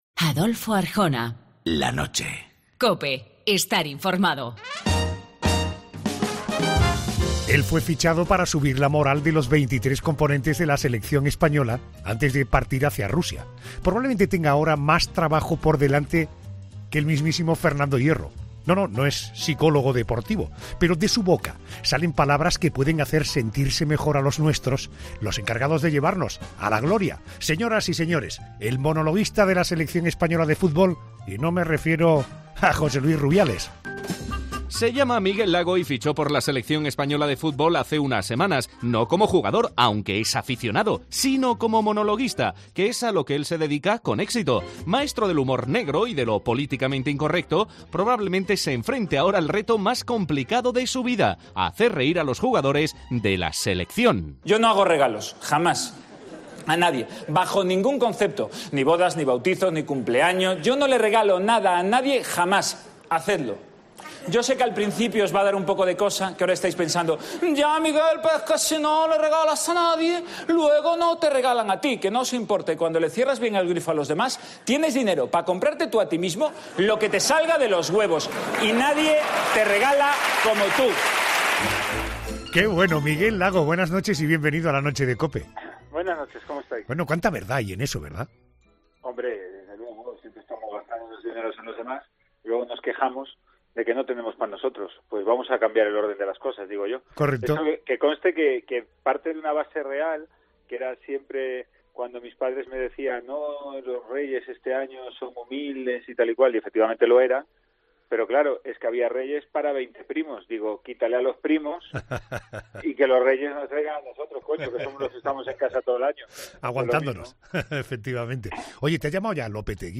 AUDIO: Entre risas, el humorista gallego Miguel Lago, reciente fichaje de la Selección Española de Fútbol, pone la nota de humor al Mundial 2018 de...